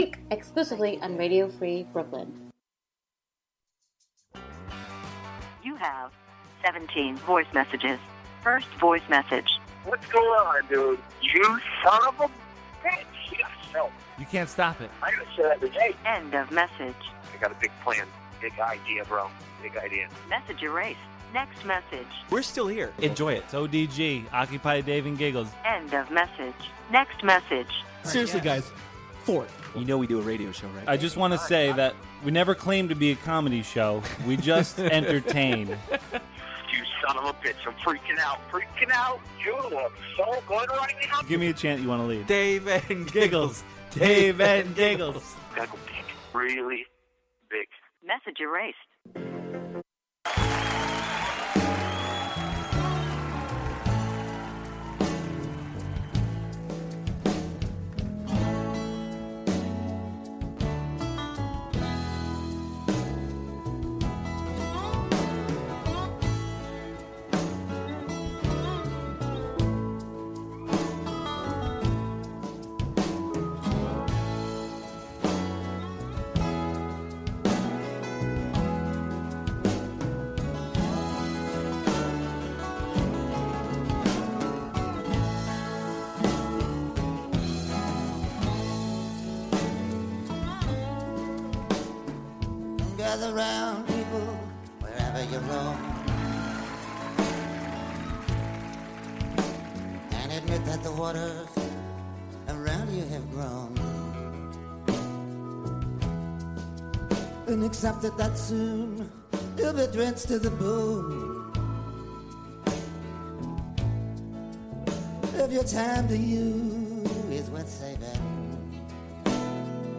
we have guests, and sometimes just ourself and a caller from time to time.